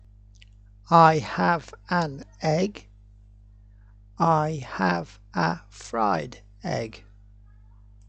(eh-g – vowel sound)
(fr-eye-d – no vowel sound)